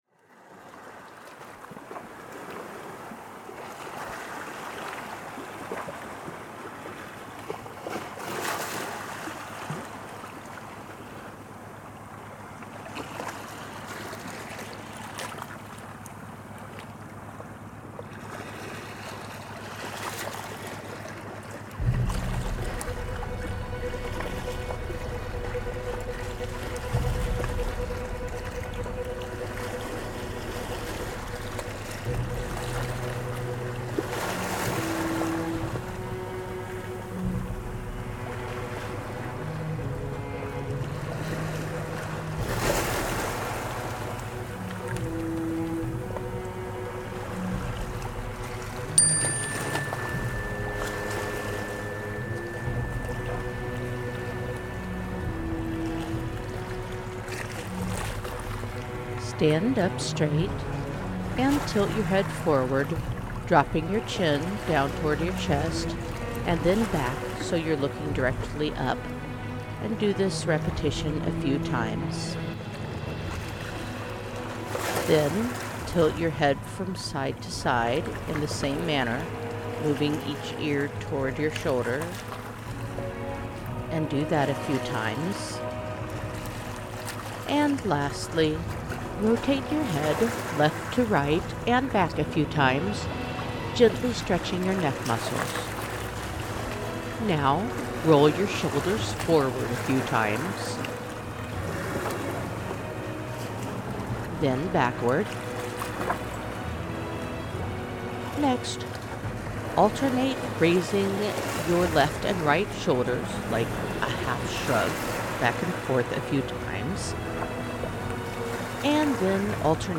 no intro version.
The guided meditation track uses a chime to begin this meditation, as do most of the meditations in this series.